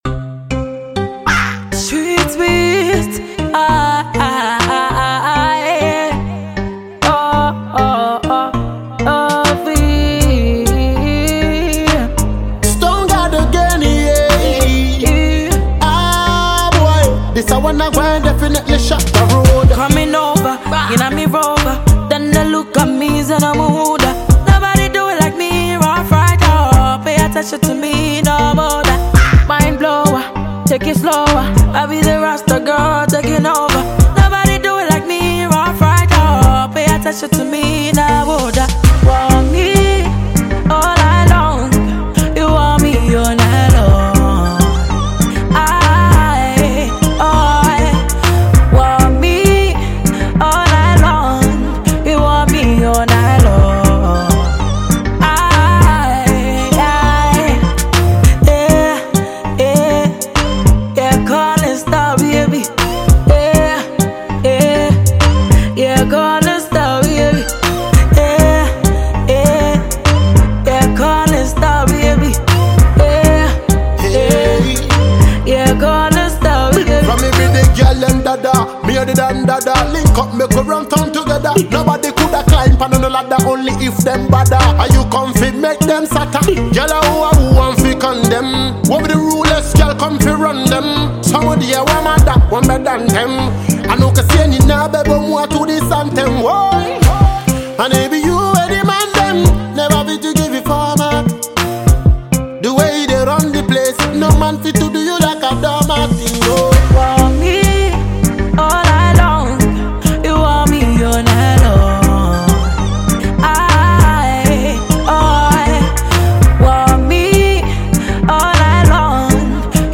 afrobeat jam